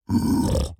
Minecraft Version Minecraft Version 1.21.5 Latest Release | Latest Snapshot 1.21.5 / assets / minecraft / sounds / mob / piglin_brute / idle5.ogg Compare With Compare With Latest Release | Latest Snapshot